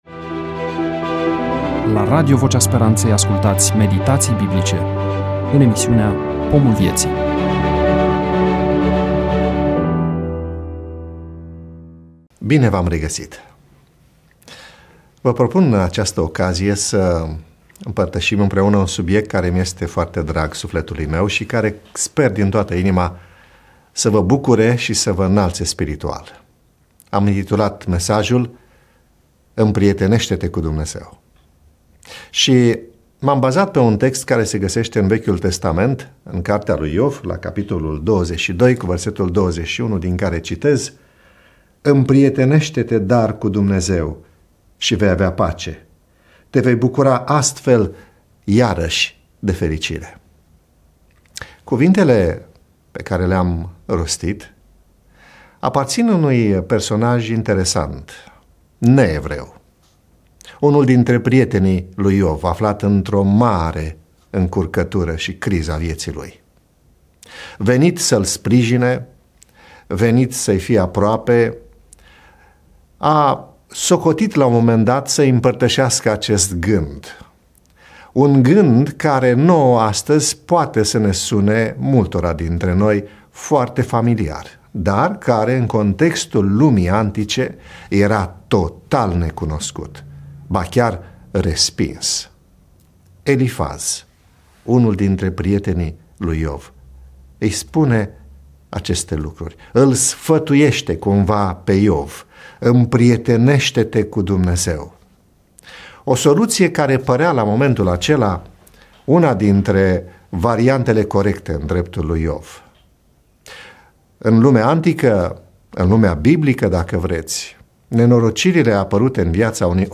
EMISIUNEA: Predică DATA INREGISTRARII: 15.05.2025 VIZUALIZARI: 12